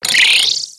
Cri de Tic dans Pokémon X et Y.